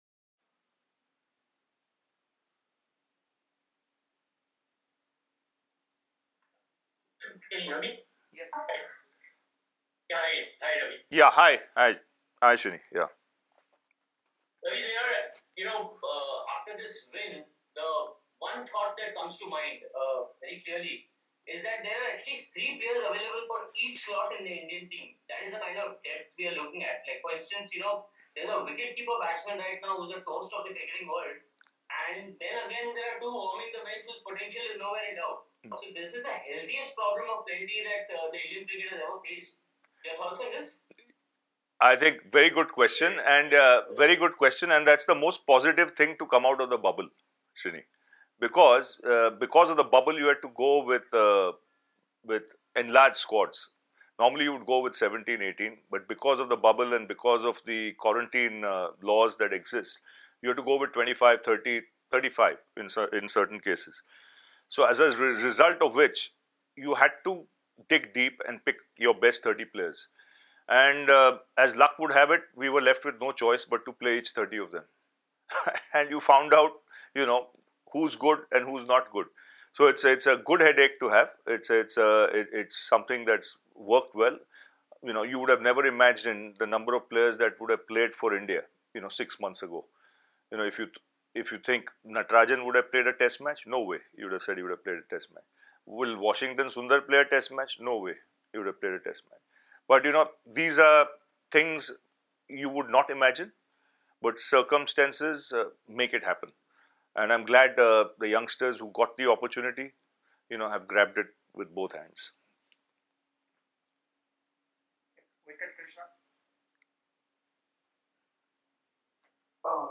Ravi Shastri, Head Coach of the Indian Cricket Team, addressed a virtual press conference in Ahmedabad on Sunday.